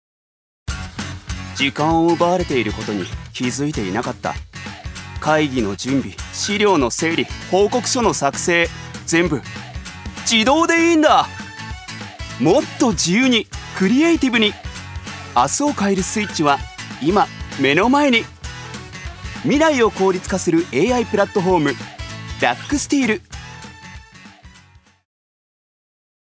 所属：男性タレント
ナレーション４